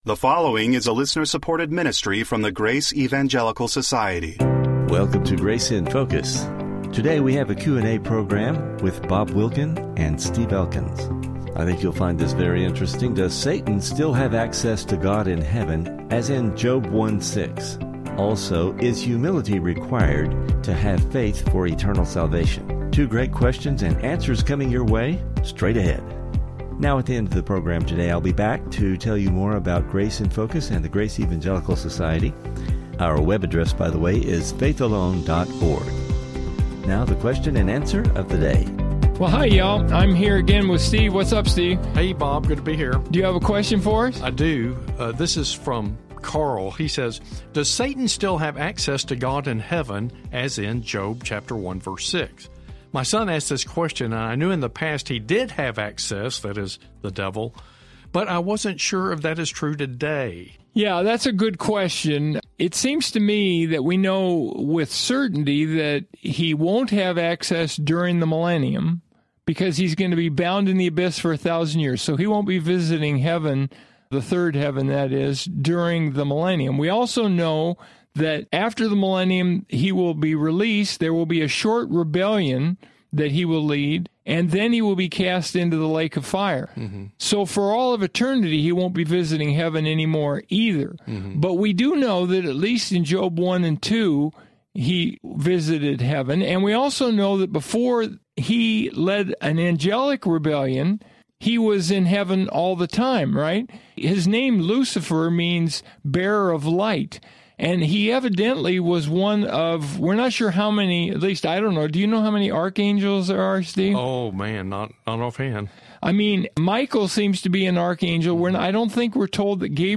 Welcome to Grace in Focus radio.
And another question about humility and faith: How are they related? A couple of really good questions from listeners.